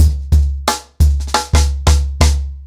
TrackBack-90BPM.15.wav